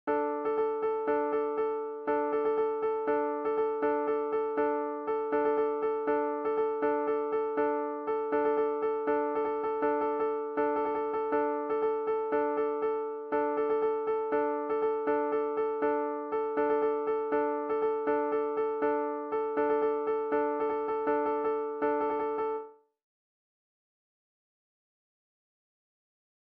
With beat, Patt 1st